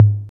808-Conga6.wav